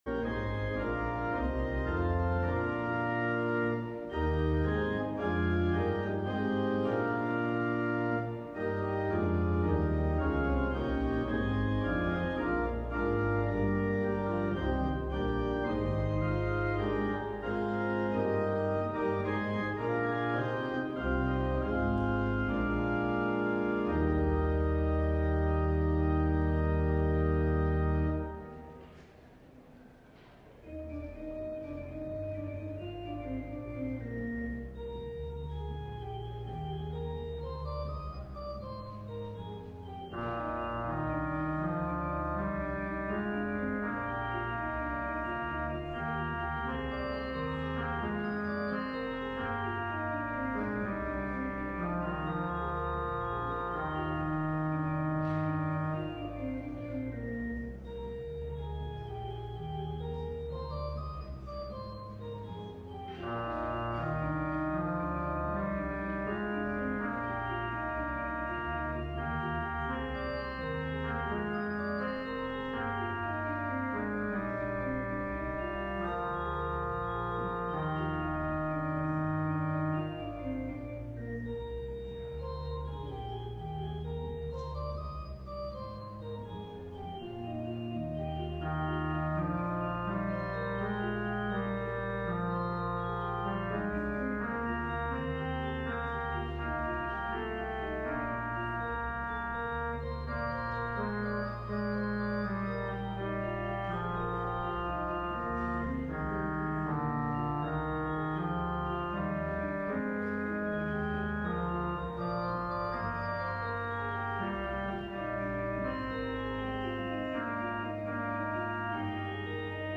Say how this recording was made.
LIVE Morning Service - What If?